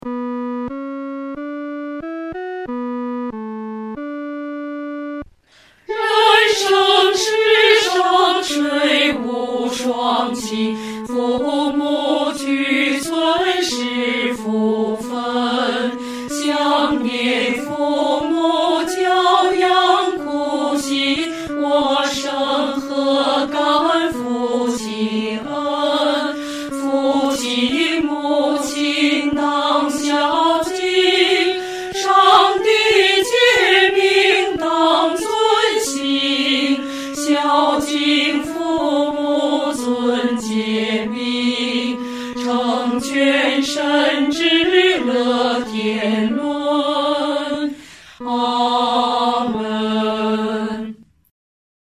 女高